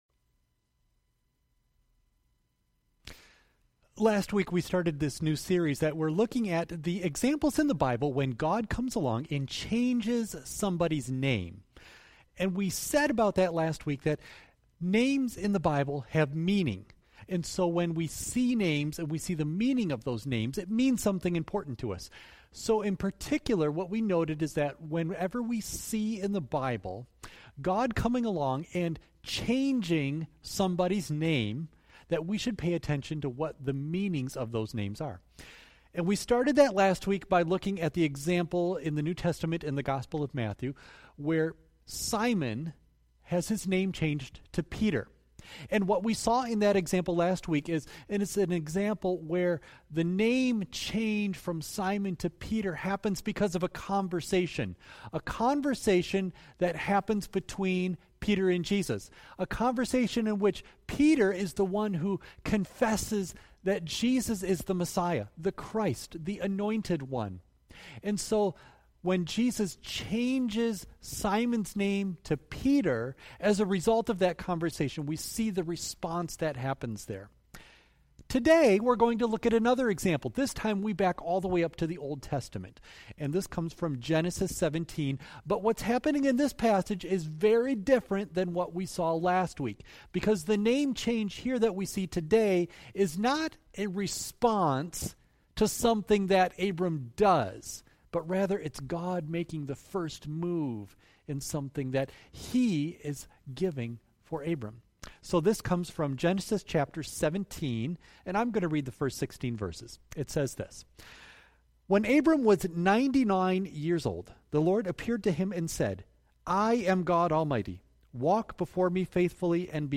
Audio only of message